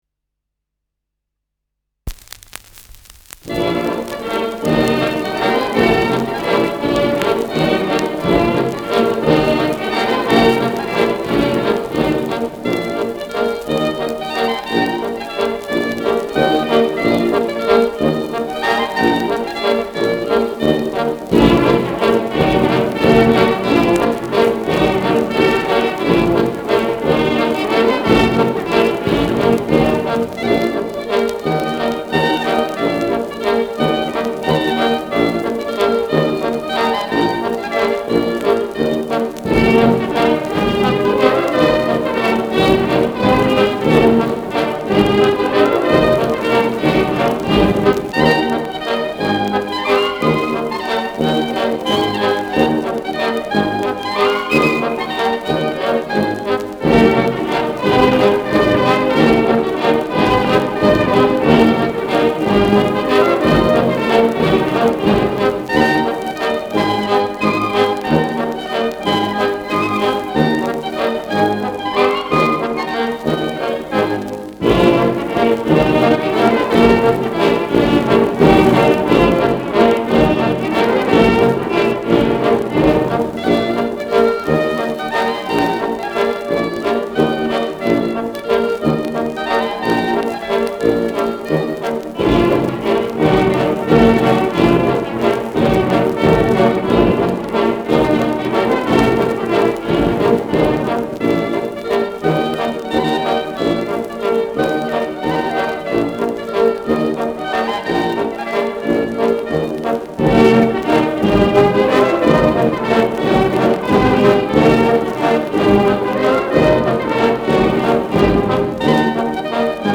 Schellackplatte
leichtes Knistern
Kapelle Hallertau (Interpretation)
[Vohburg an der Donau] (Aufnahmeort)